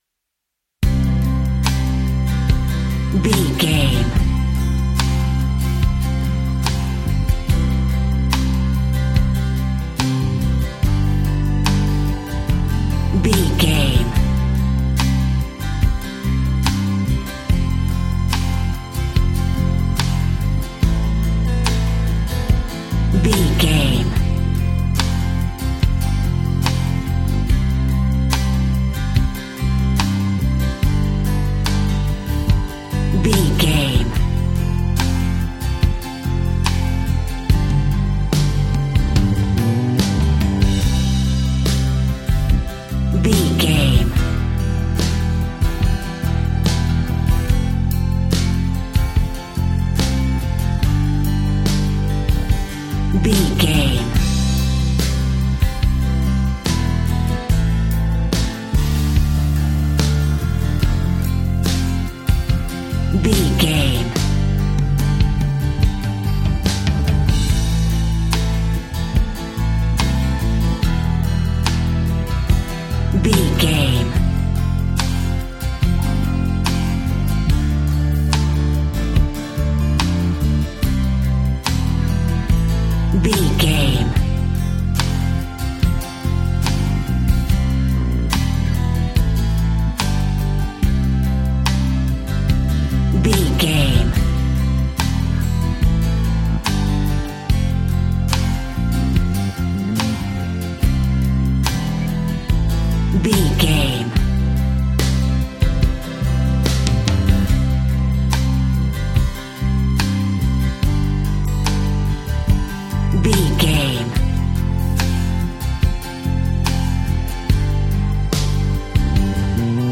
Ionian/Major
sweet
happy
acoustic guitar
bass guitar
drums